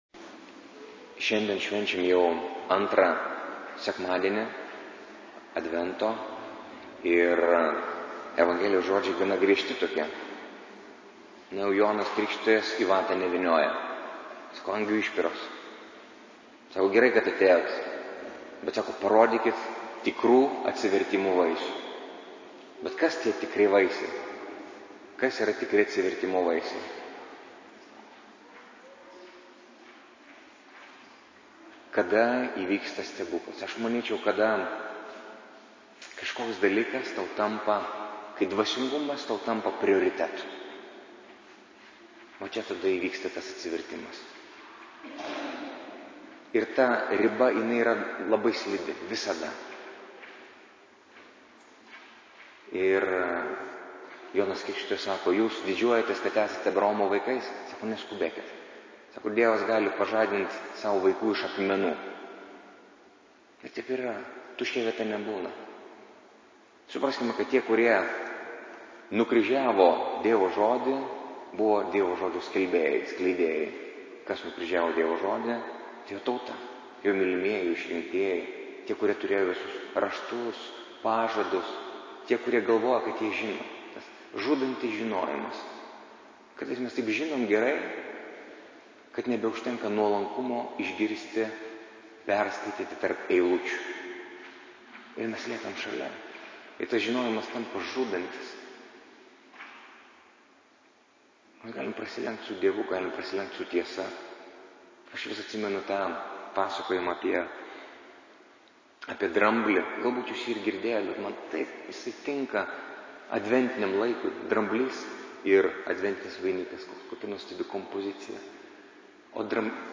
Audio pamokslas Nr1: 2016-12-04-ii-advento-sekmadienis